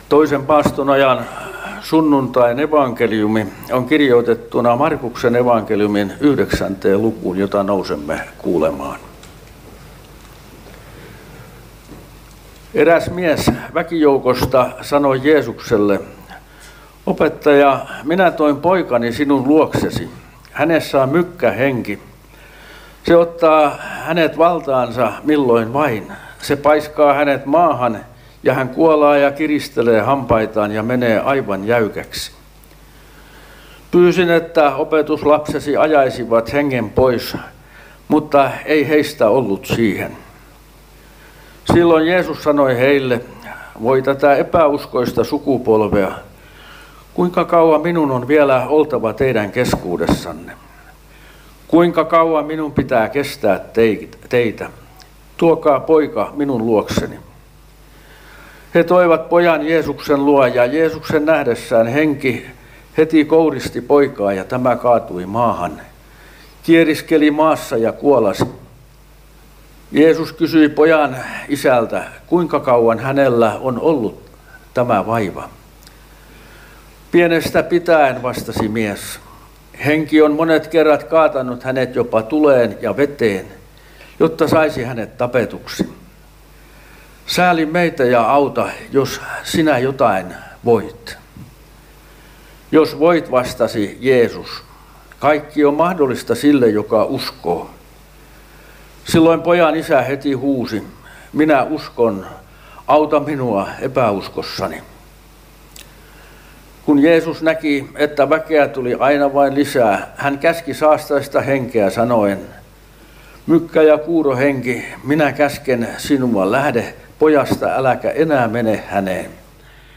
Karkku